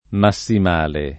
[ ma SS im # le ]